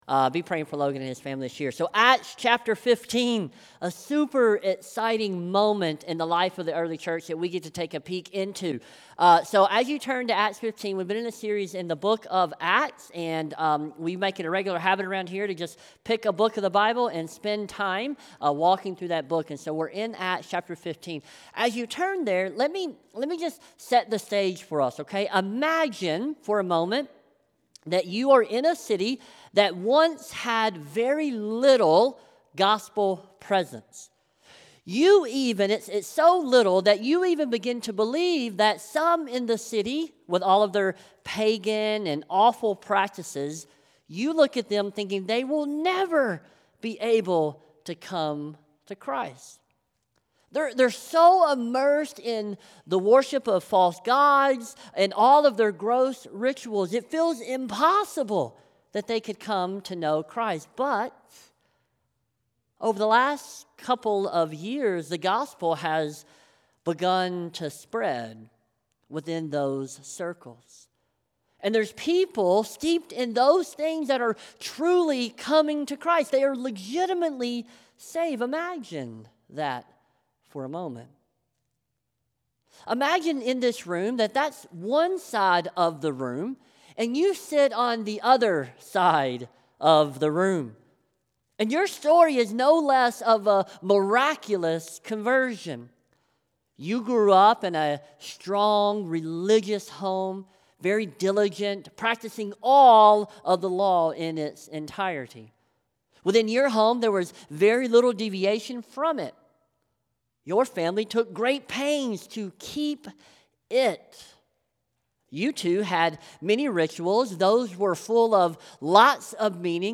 SERMON | Acts 15: 1-35 | Jerusalem Council | Light in the Desert Church